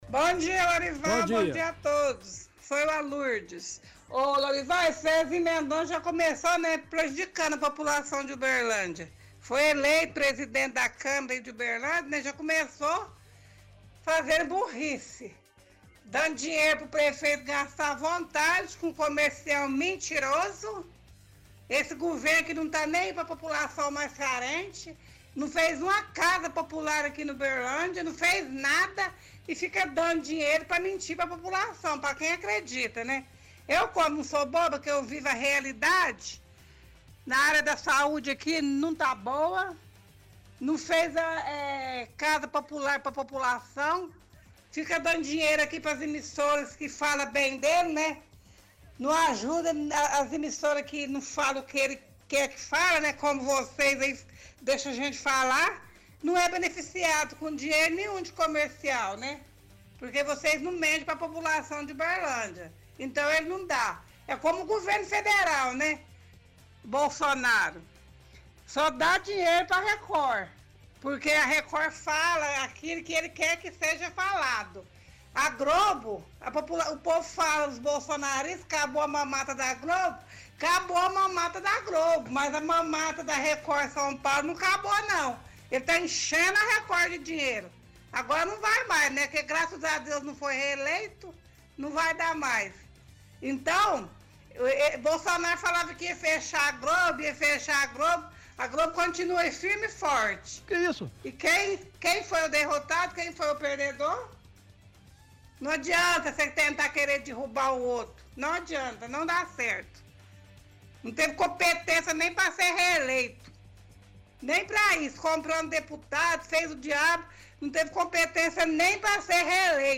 – Ouvinte reclama que a presidência do Zezinho Mendonça já começou mal, dando dinheiro para as propagandas do prefeito.